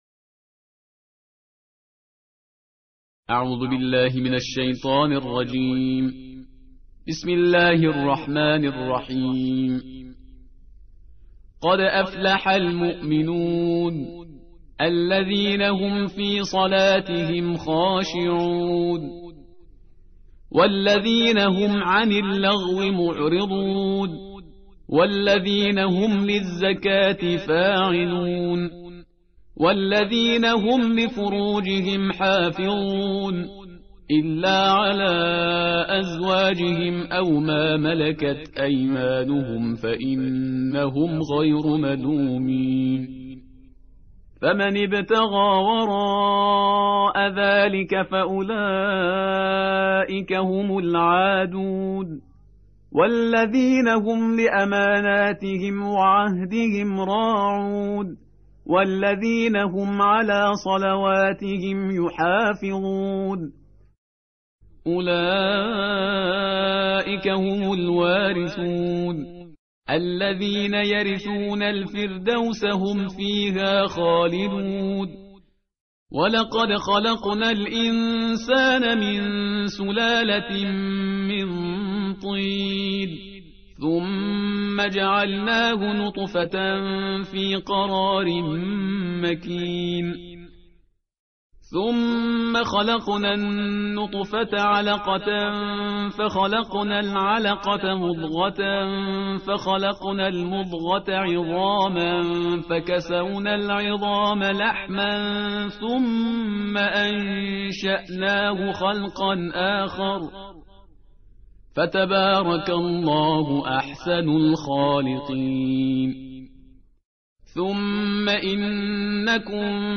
تلاوت جزء هجدهم قرآن مجید با صدای استاد شهریار پرهیزکار